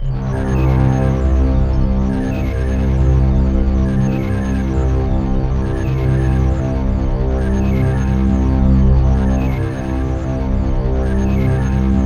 Index of /90_sSampleCDs/USB Soundscan vol.13 - Ethereal Atmosphere [AKAI] 1CD/Partition C/05-COMPLEX